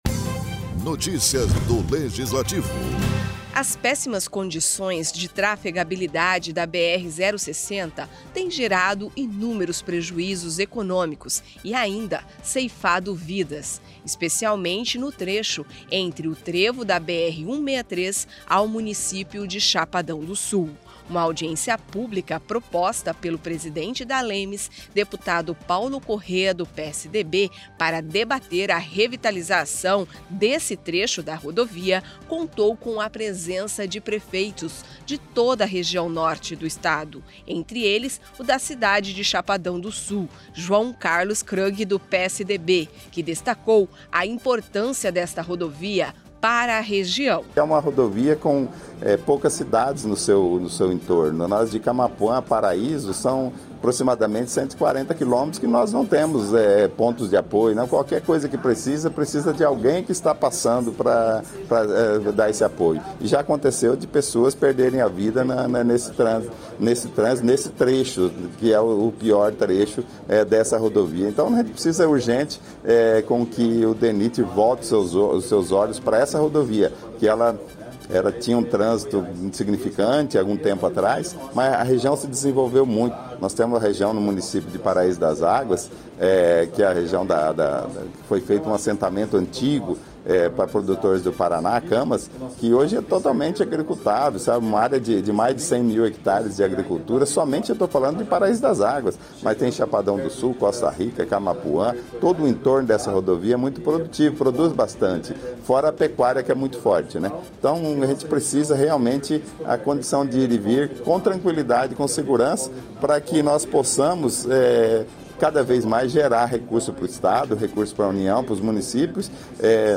Durante audiência, prefeitos e representantes de entidades defendem revitalização da BR060